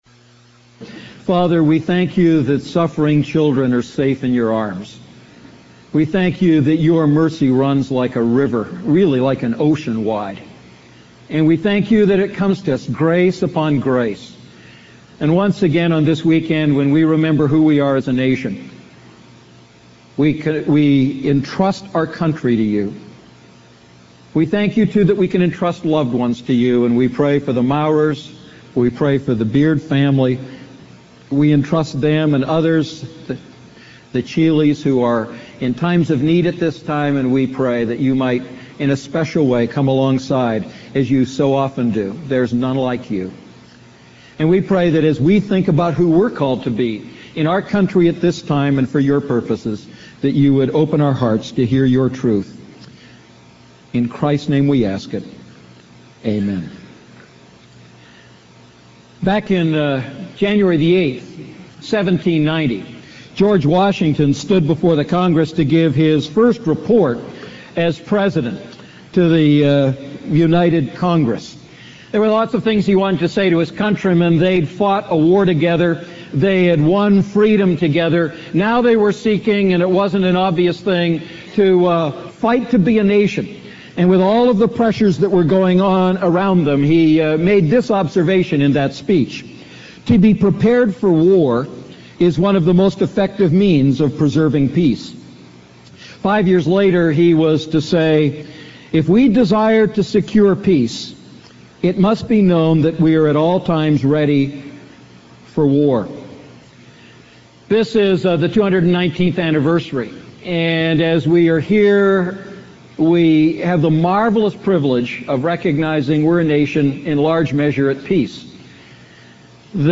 A message from the series "Wise Walking."